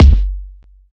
Kick (31).wav